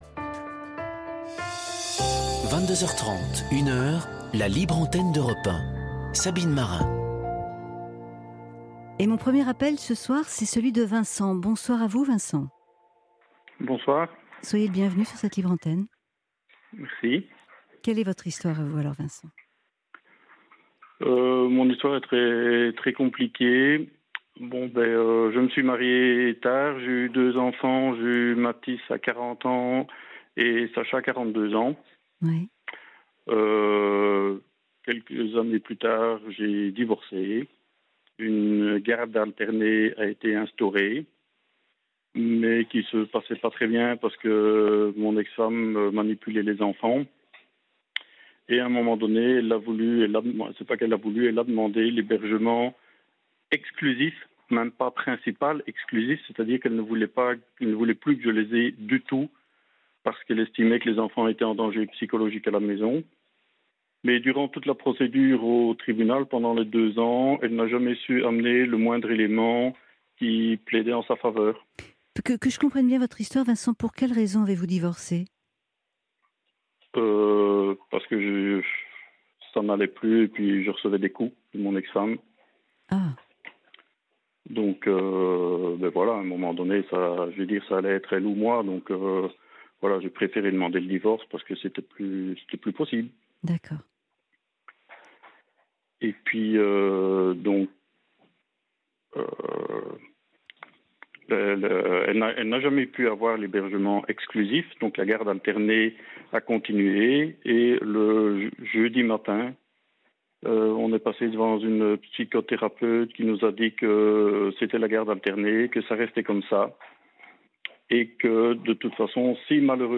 Un extrait de l’émission Libre antenne diffusée par Europe 1